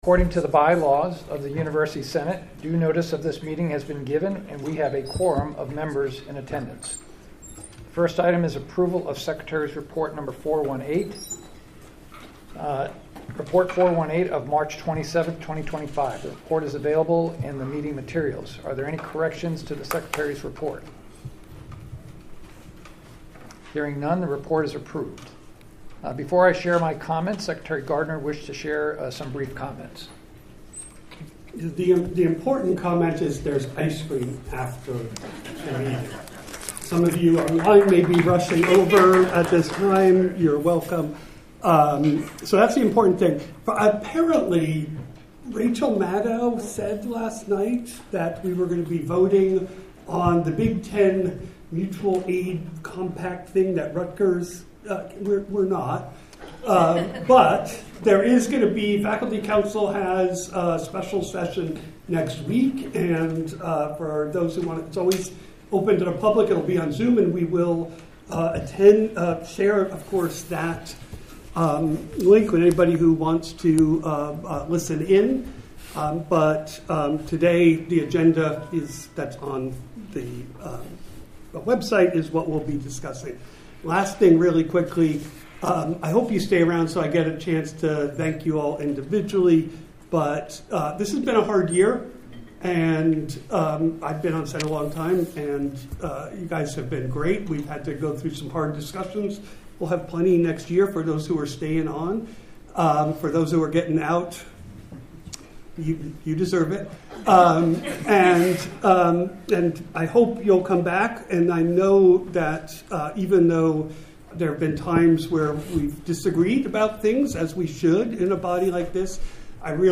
This meeting will be a hybrid meeting.
President Ted Carter will preside.